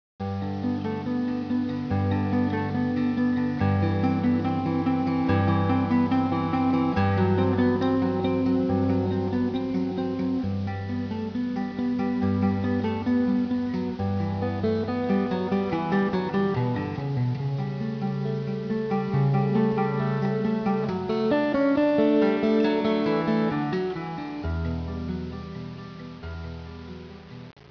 Here is an example of cello being played pizzicato:
cello.au